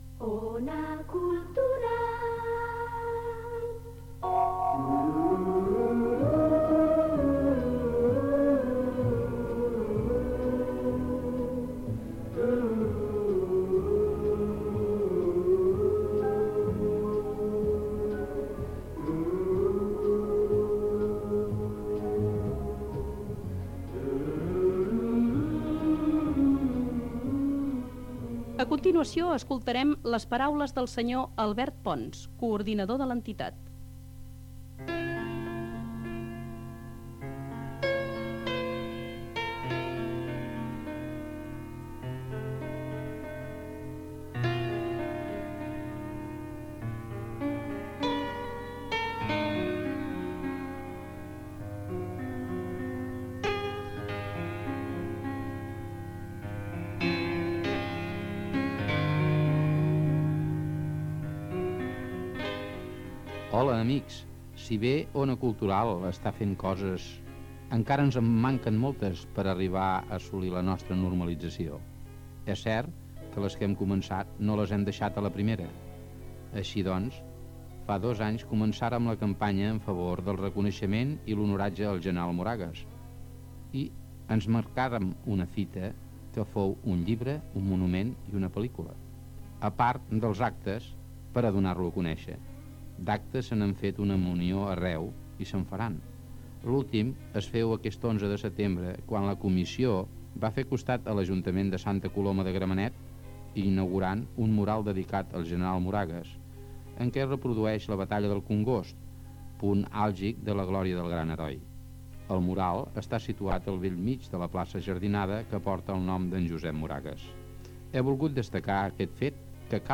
Informatiu
FM
Programa distribuït en forma de casset.